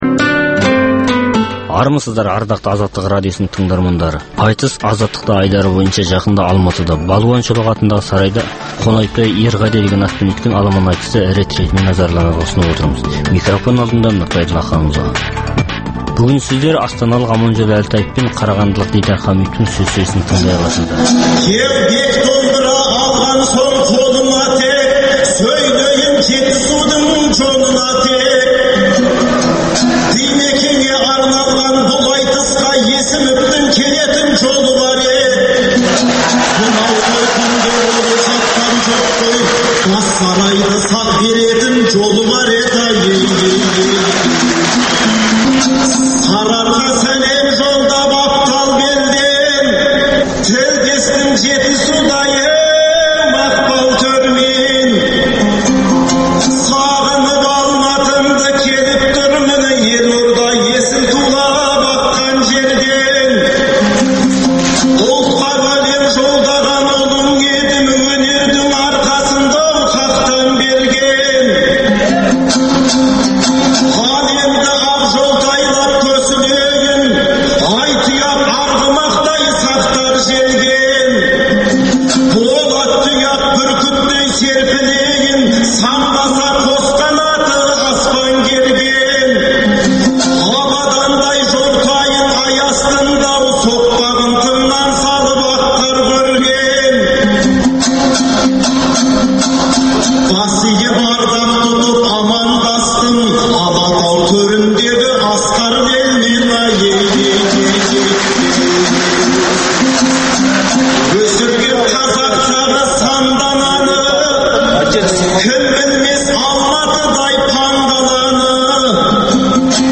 Айтыс - Азаттықта
Ақпанның 11-12 күндері өткен Алматыдағы аламан айтыста